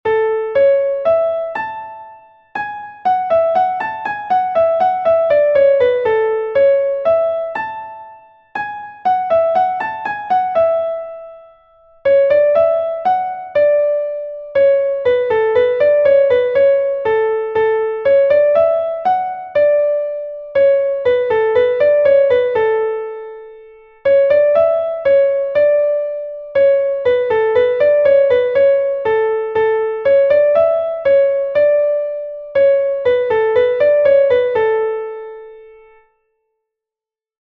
Bourrée from Brittany